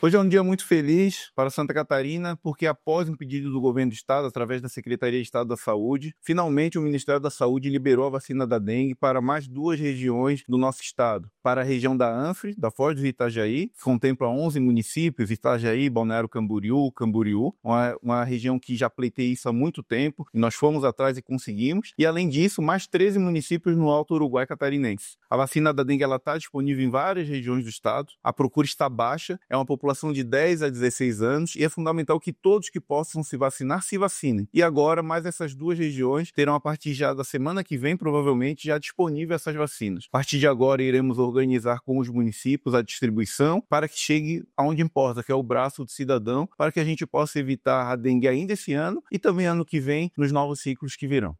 O Secretário de Estado da Saúde, Diogo Demarchi, destaca a importância da vacinação para ajudar a evitar a dengue nesse e nos próximos ciclos que virão: